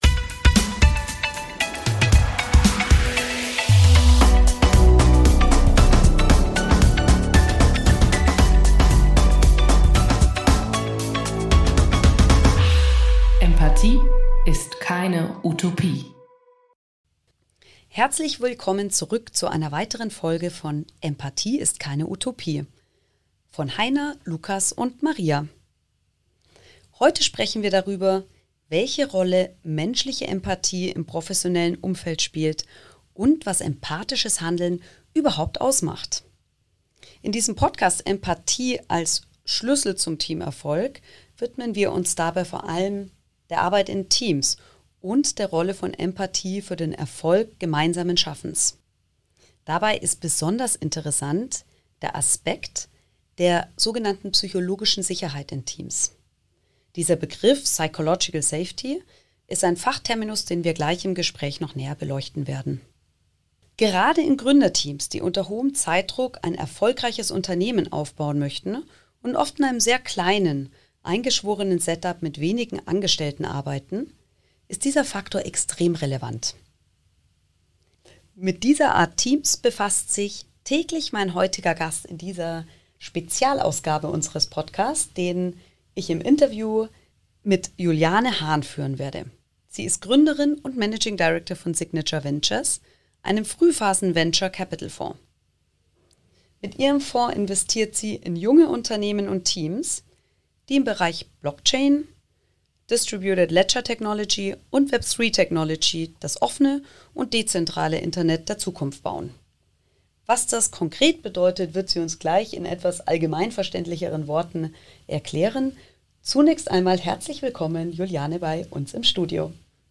#8 Interview